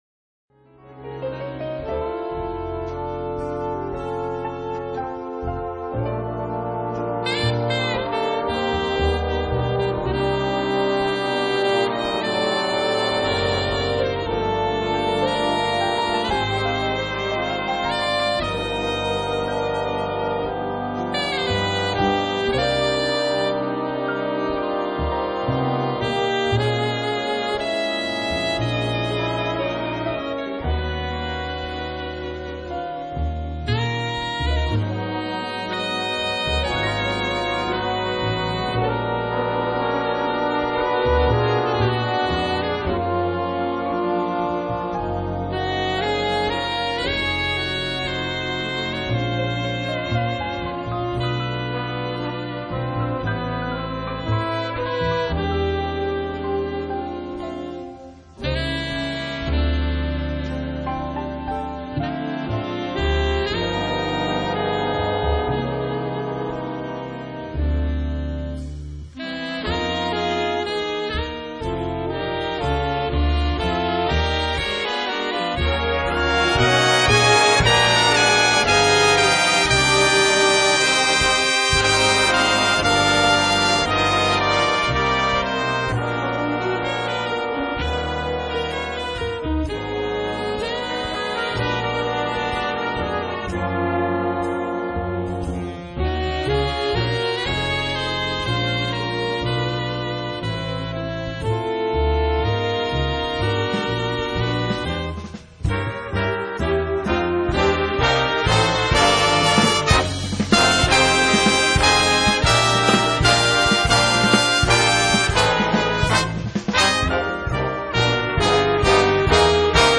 Big band jazz